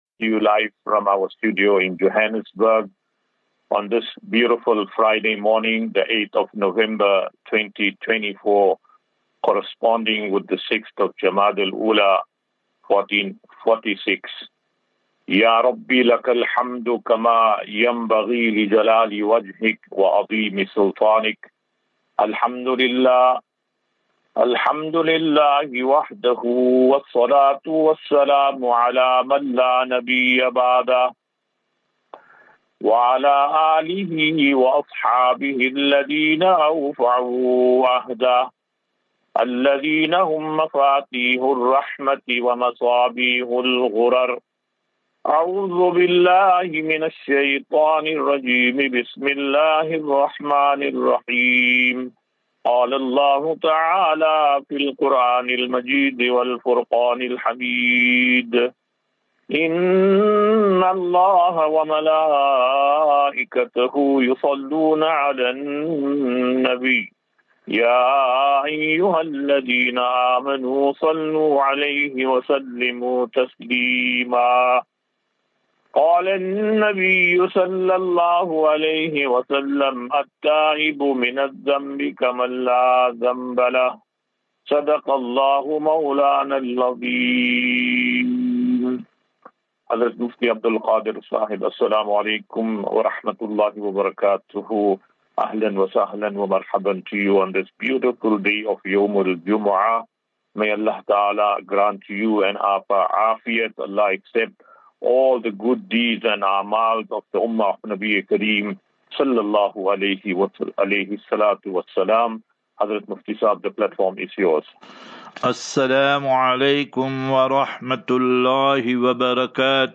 QnA